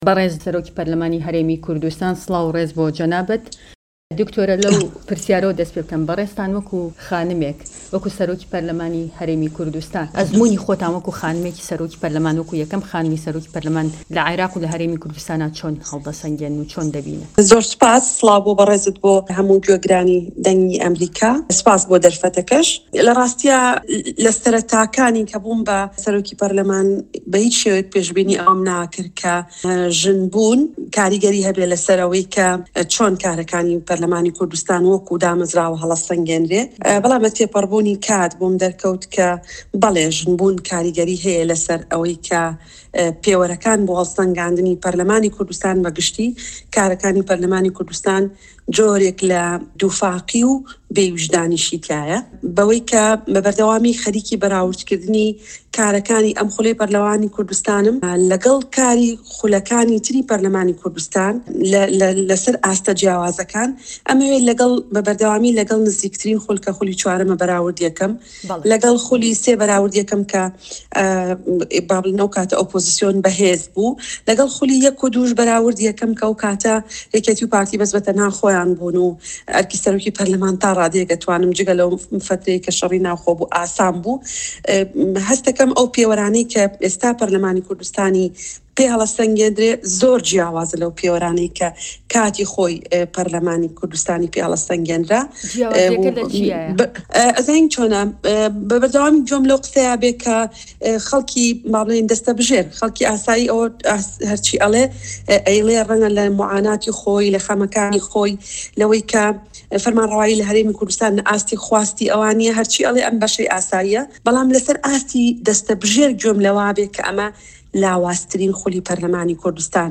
Fully interview
Speaker of Kurdistan Parliament-Iraq Dr. Rewz faiq